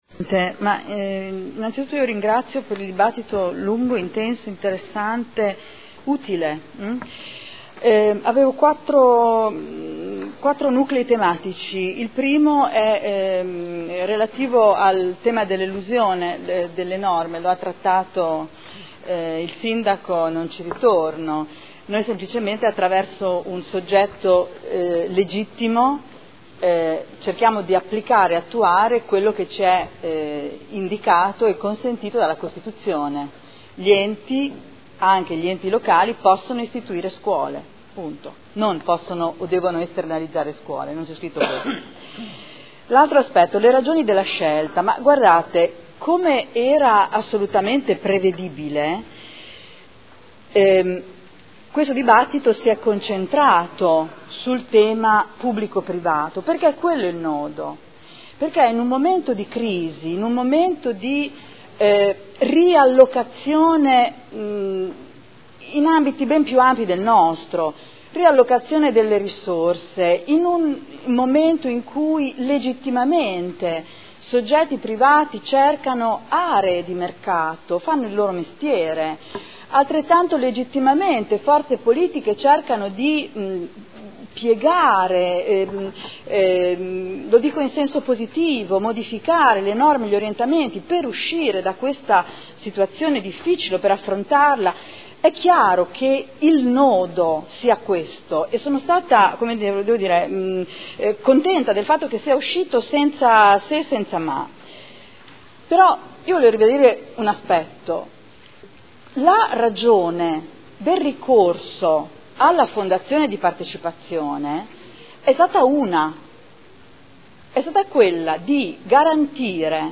Adriana Querzé — Sito Audio Consiglio Comunale